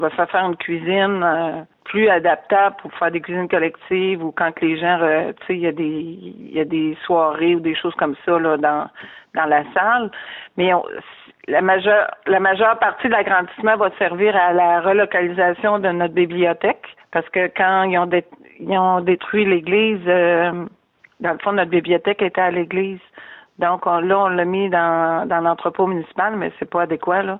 La mairesse, Ginette Deshaies, a fait part de l’importance de cet agrandissement.